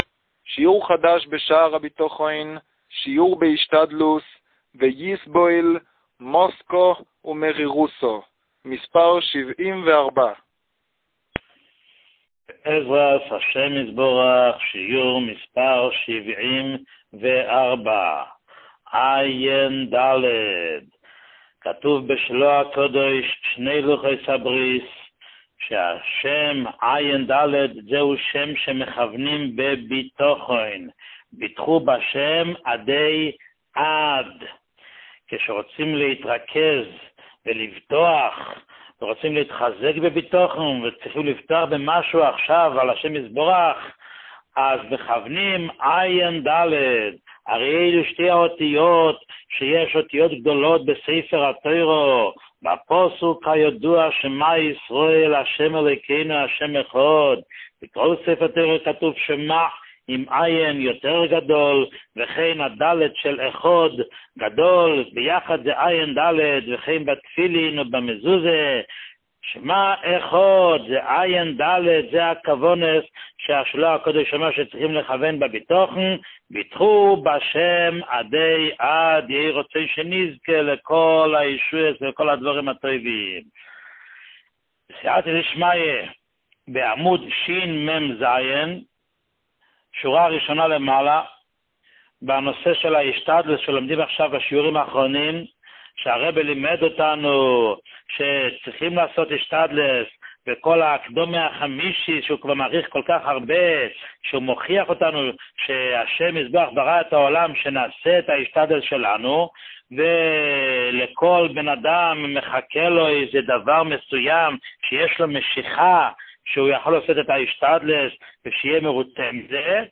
שיעור 74